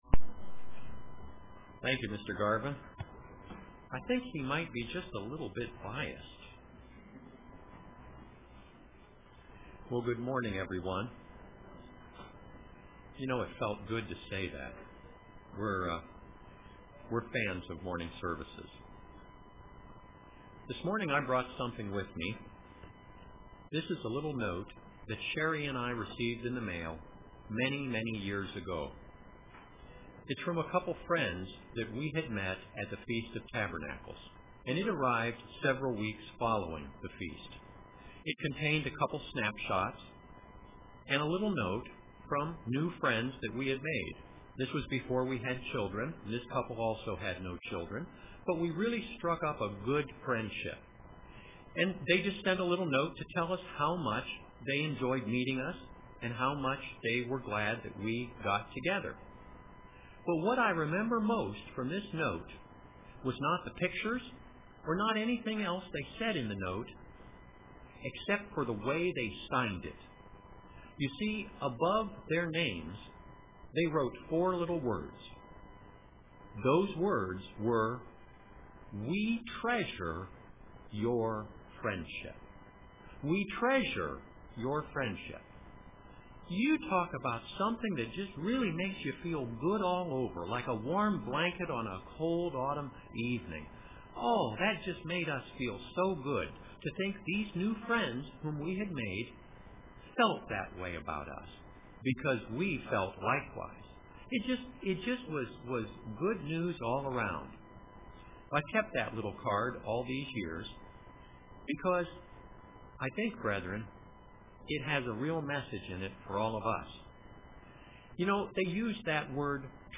Print Treasuring Our Calling UCG Sermon Studying the bible?